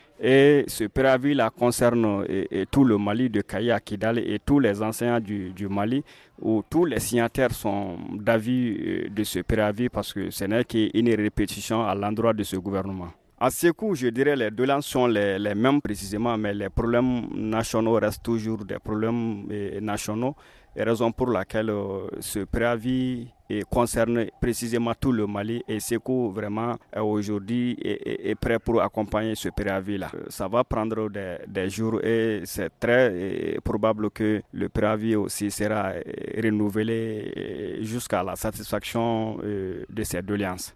REACTION-ENSEIGNANTS-SEGOU-FR.mp3